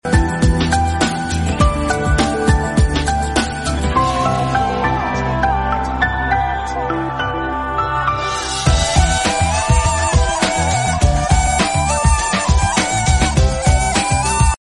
Barish ⛈🌧 Enjoy 😊 sound effects free download